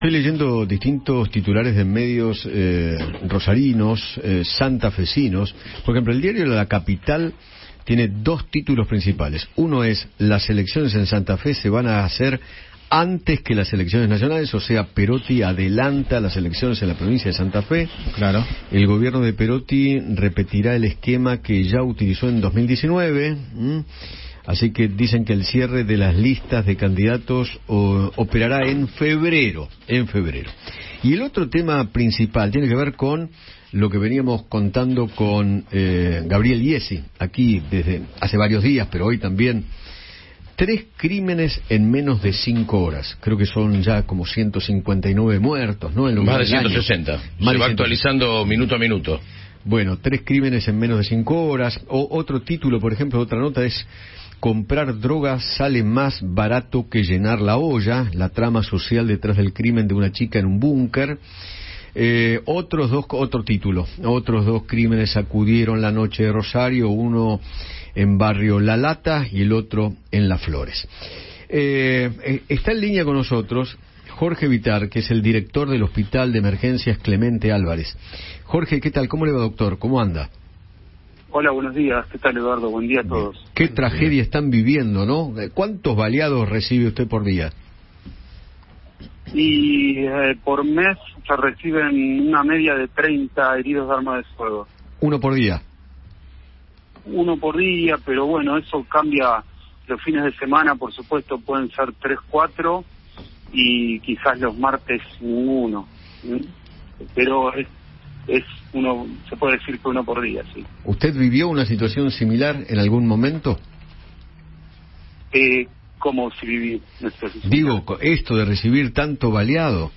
conversó con Eduardo Feinmann sobre la preocupante situación que atraviesa la ciudad santafesina como consecuencias del narcotráfico.